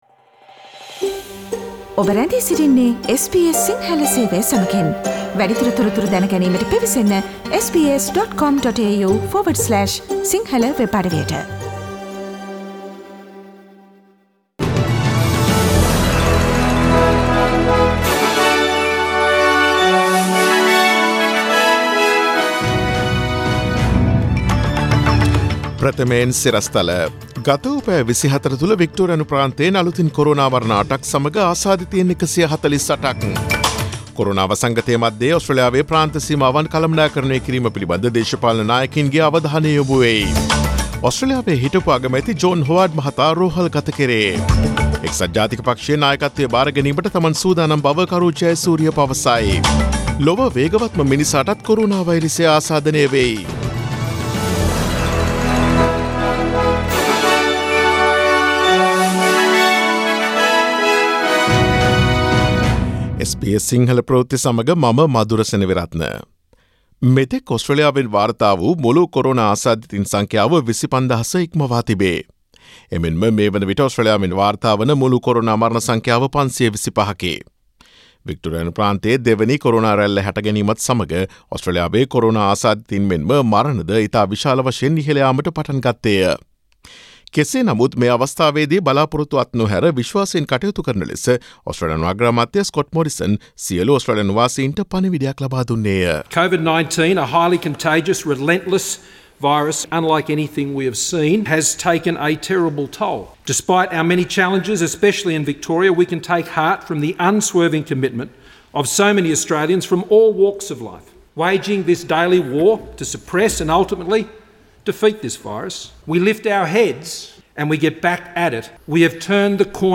Daily News bulletin of SBS Sinhala Service: Tuesday 25 August 2020